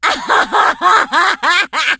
diva_kill_vo_06.ogg